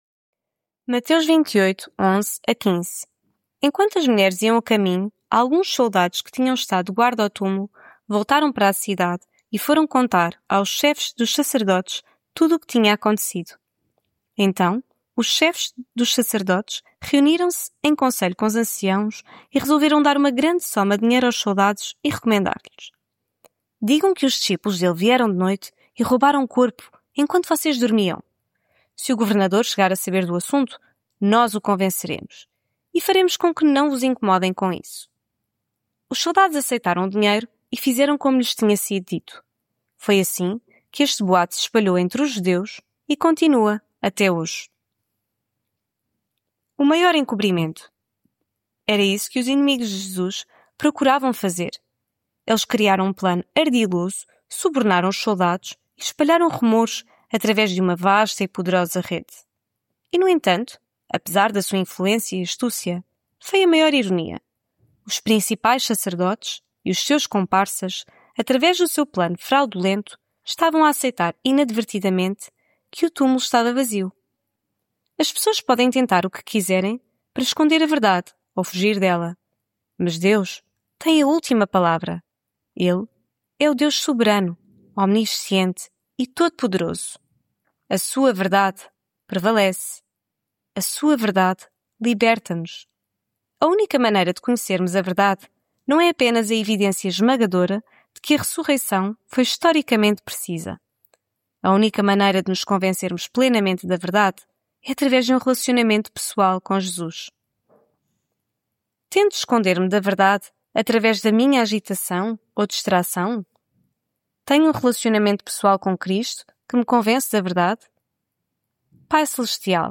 Devocional Quaresma